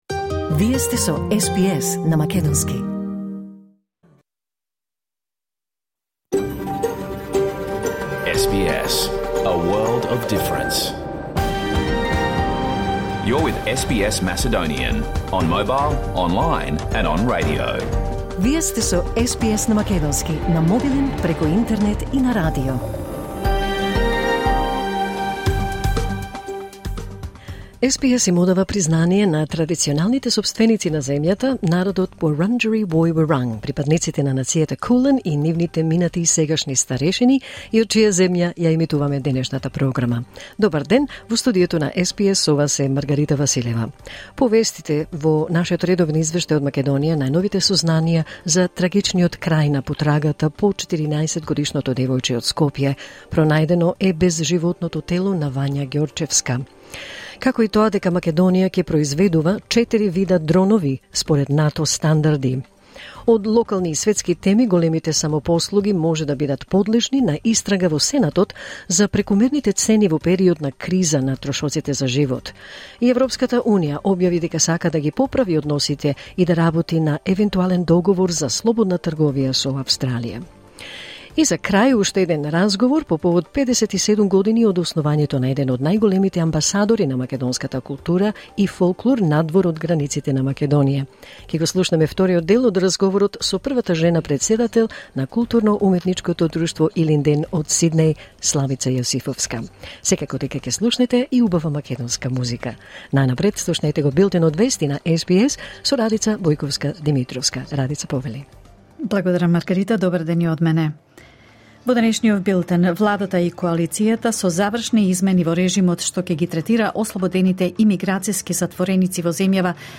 SBS Macedonian Program Live on Air 4 December 2023